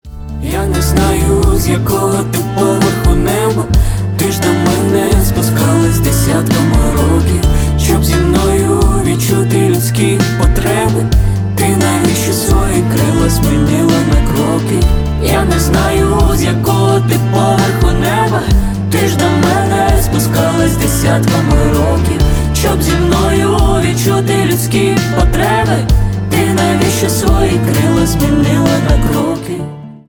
поп
дуэт